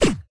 Launch.wav